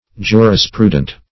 Search Result for " jurisprudent" : The Collaborative International Dictionary of English v.0.48: Jurisprudent \Ju`ris*pru"dent\, a. [See Jurisprudence .]